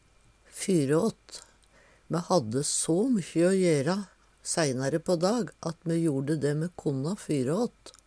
fyryåt - Numedalsmål (en-US)
DIALEKTORD PÅ NORMERT NORSK fyryåt på førehand, før det skal skje noko meir Eksempel på bruk Me hadde so mykkje o jera seinare på dag so me jorde det me konna fyryåt.